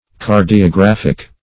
Meaning of cardiographic. cardiographic synonyms, pronunciation, spelling and more from Free Dictionary.
cardiographic.mp3